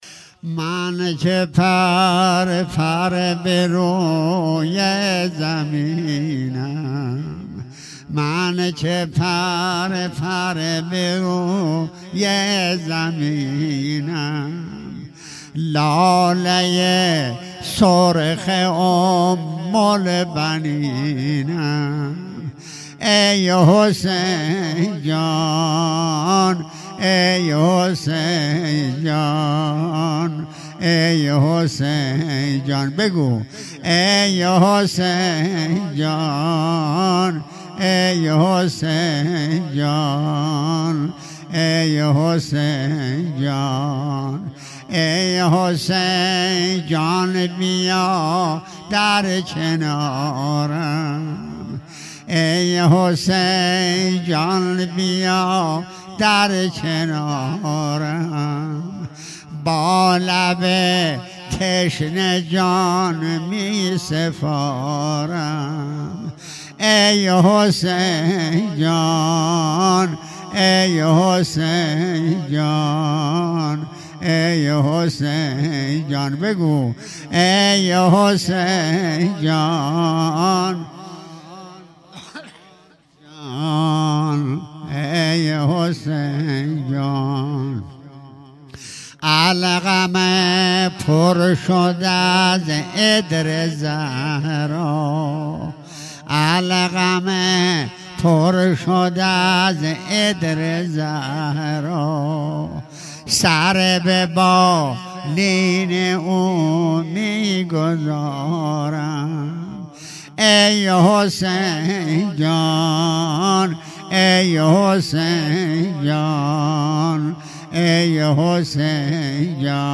روضه انصارالزهرا سلام الله علیها
اقامه عزای روضه حضرت صدیقه شهیده علیها السلام _ شب دوم